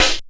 Snare 28.wav